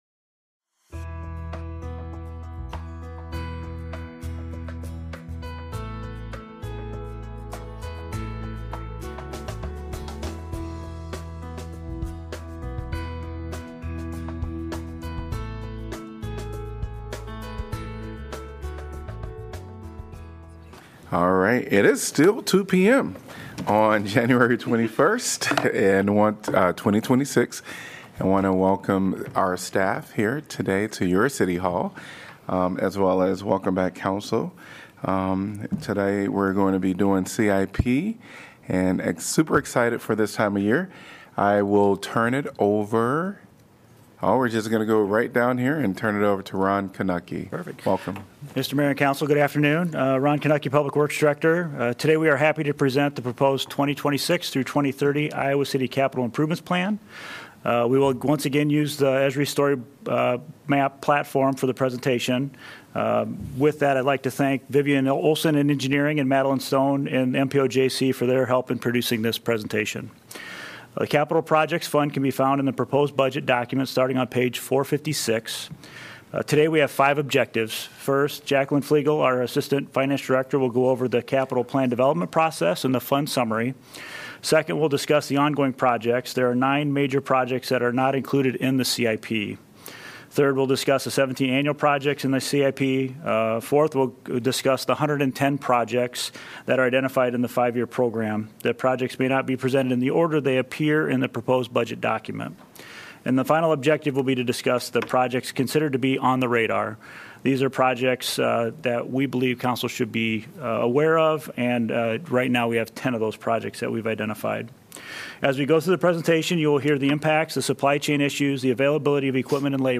Iowa City City Council Budget Work Session of January 21, 2026 - Capital Improvement Projects